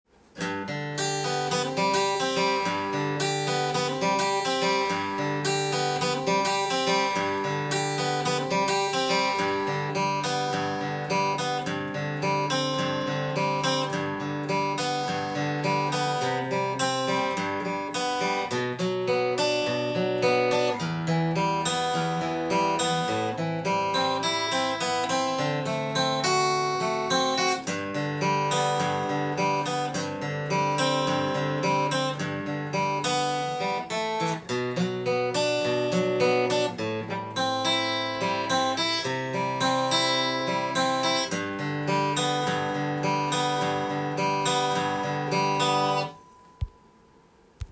譜面通り弾いてみました
アコースティックギターで弾いていきますが
イントロで早速アルペジオ奏法に　おまけでついてくるハンマリングが
非常にシンプルなアルペジオパターンになりますので
８分音符のみでリズムがとりやすくなります。